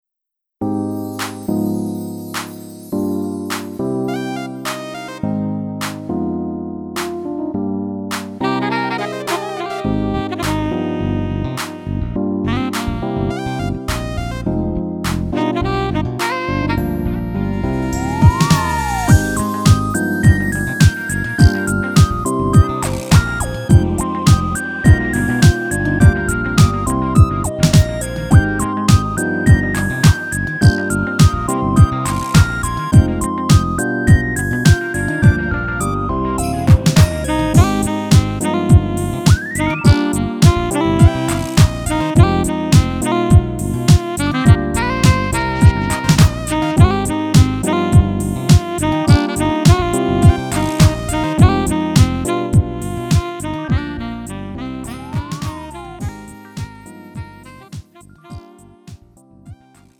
음정 원키
장르 구분 Lite MR